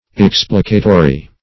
Explicatory \Ex"pli*ca`to*ry\, a.